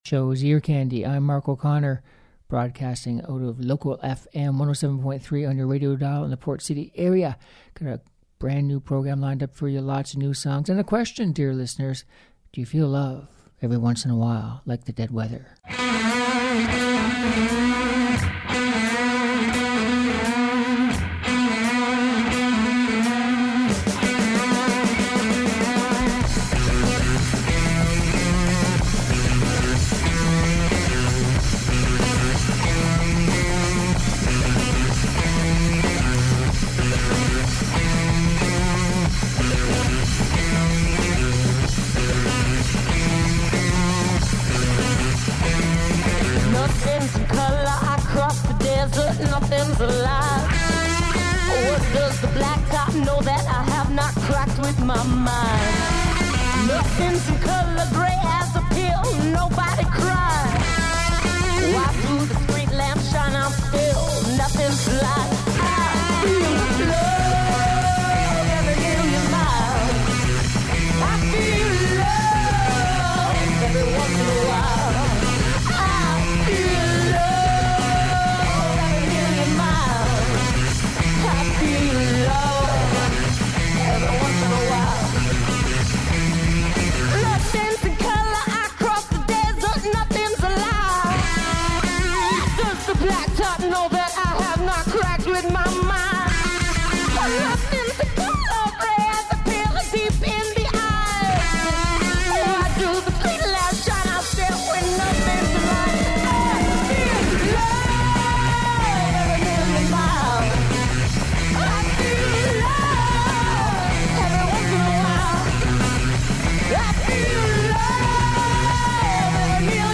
Catchy Uptempo Music From Old and New Artists